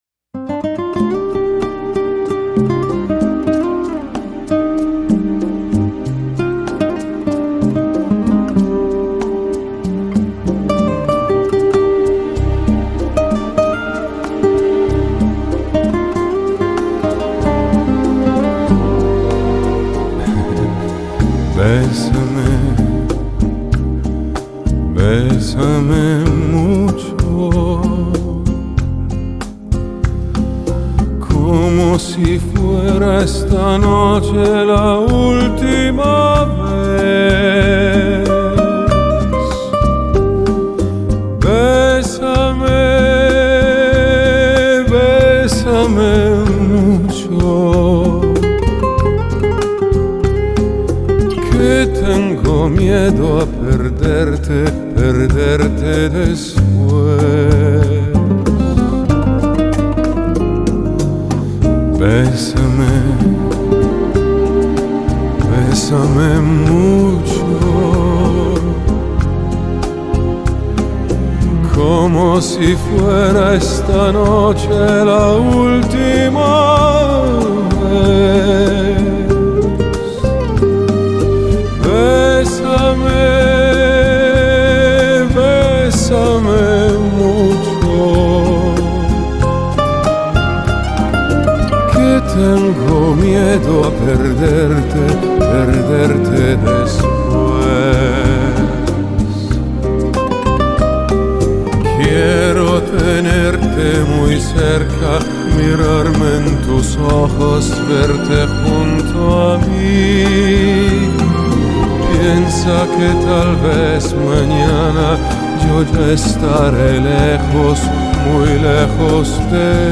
Solo Recorder